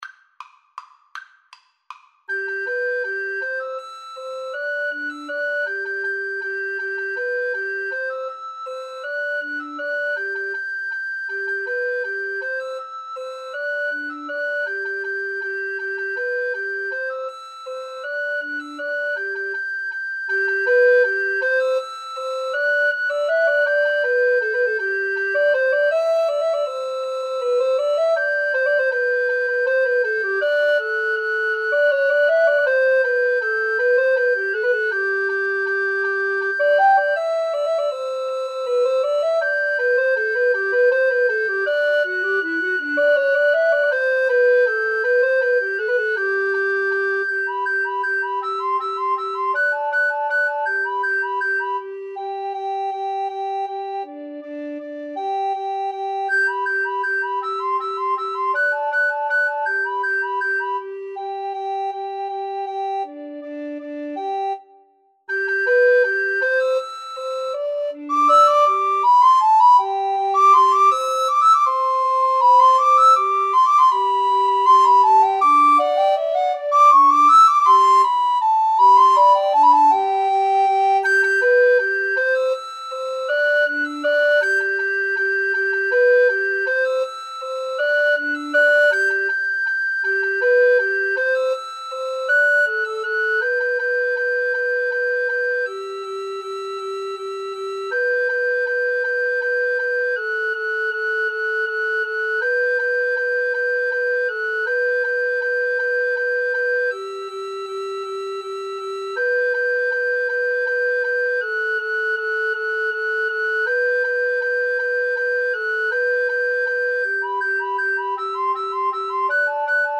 Allegro Vivo = 160 (View more music marked Allegro)
Recorder Trio  (View more Intermediate Recorder Trio Music)
Classical (View more Classical Recorder Trio Music)